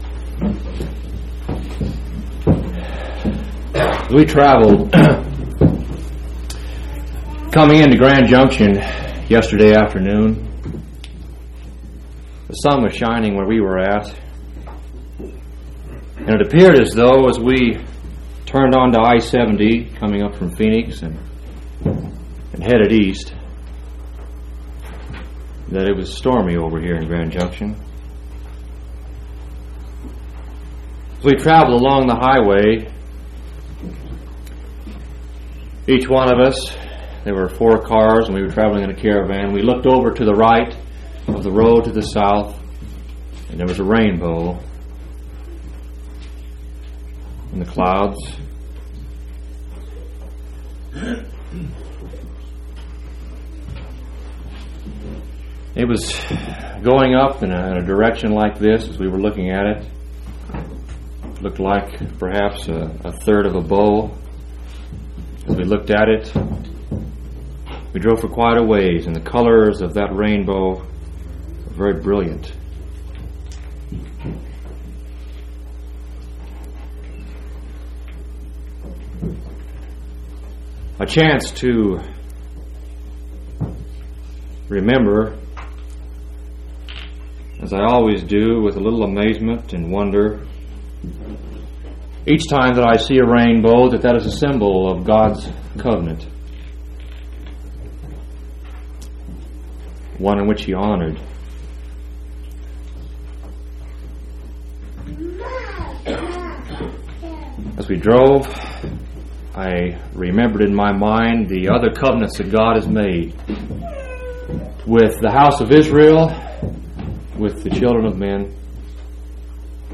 6/9/1984 Location: Colorado Reunion Event: Colorado Reunion